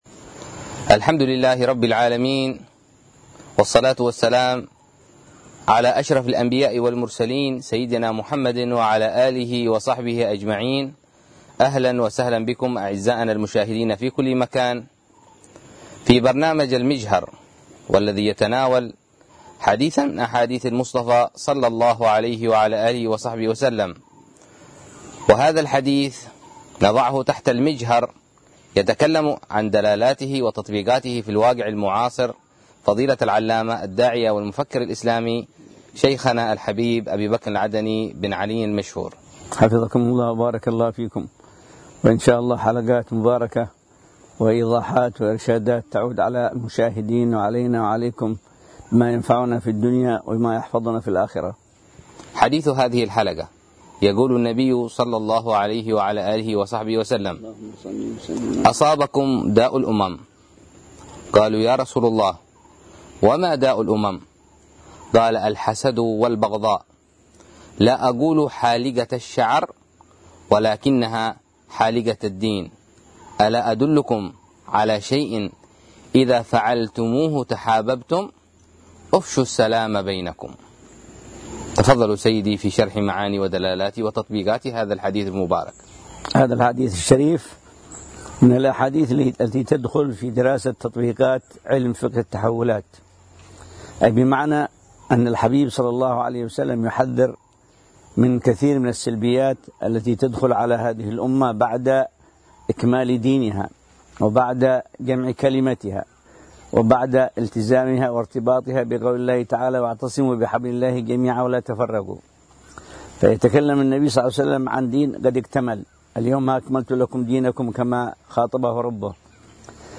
برنامج حواري فكري